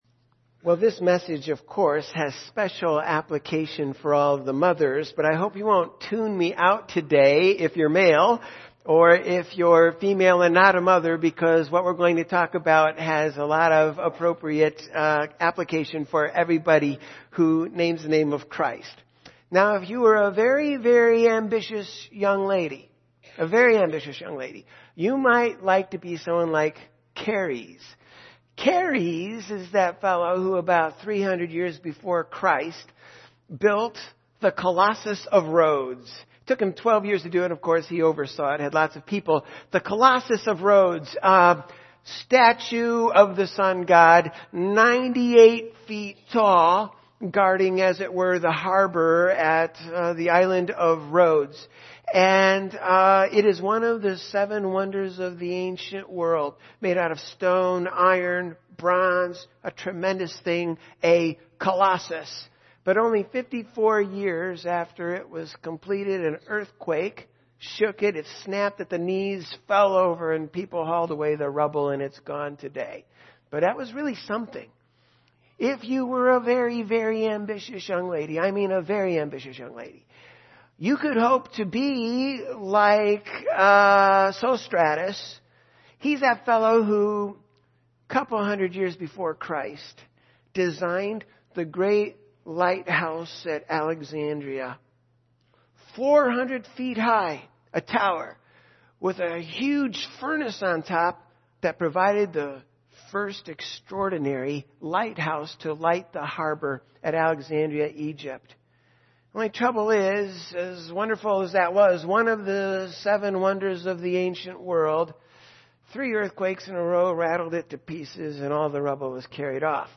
Mothers Day Service 2015